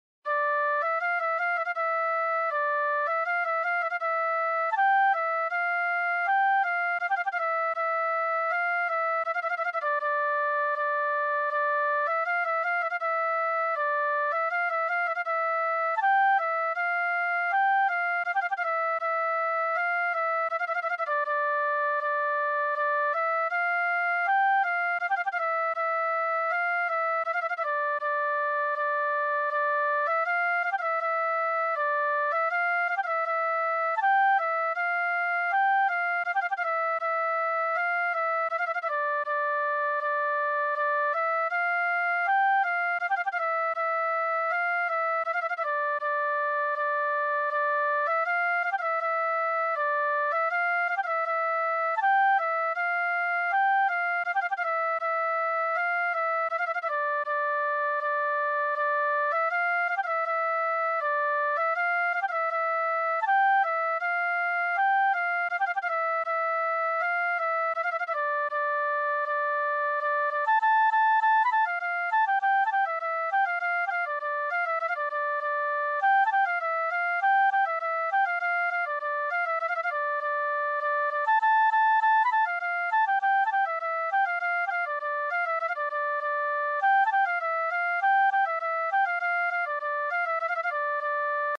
Msho Sarer Mshush E (Khorotik), Ninare – Partition Shvi / Մշո Սարեր Մշուշ է (Խորոտիկ) , Նինարե – Նոտաներ Շվիի համար
Auteur: Chansons folkloriques arméniennes